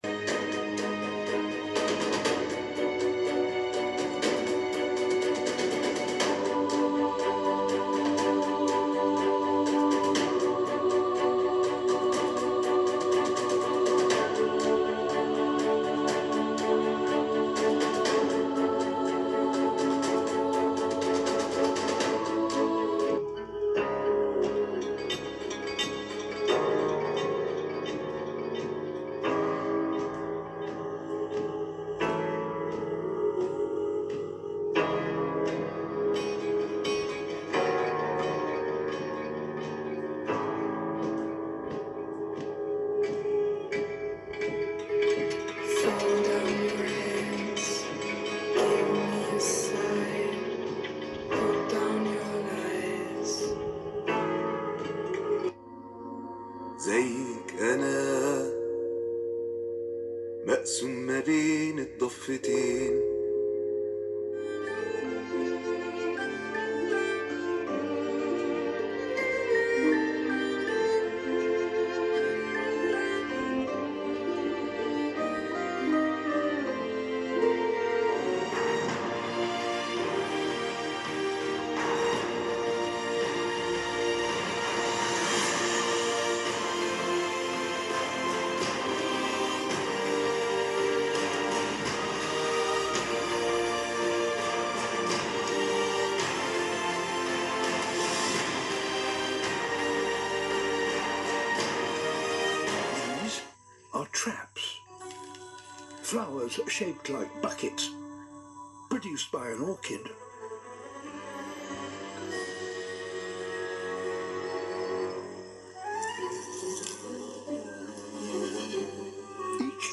نوضح في هذا الجزء مدي قوة الصوت و جودته مع تقديم الإزعاج الخاص بالتبريد في الأوضاع المختلفة و يتم القياس من مسافة 25 سم وهو ما يمثل بعد رأس المستخدم عن الجهاز لمحاكاة الضوضاء الفعلية من الجهاز و قوة السماعات كذلك
الجهاز يقدم 4 سماعات مدمجة بقوة  4x2w مع علو صوت مرتفع يقدر ب 82 ديسيبل و صوت السماعات جيد جدا سواء مع أستخدام برنامج Dolby الملحق و سأترك لكم تجربة صوتية للجهاز ولكن مع تفعيل البرنامج!
التجربة الصوتية مع تفعيل تقنيات Dolby
صوت السماعات واضح للغاية و ربما أكثر نقاء من جهاز x16 ولكنة متوسط وهو مناسب للألعاب و الموسيقي و الأغاني و الأفلام ، الـ Bass جيد بفضل تصميم السماعات بصورة عكسية و الـ Triple جيد جدا  ولكن في هذه الفئة السعرية كان يمكن ان تقدم الشركة افضل من هذا بكل تأكيد  !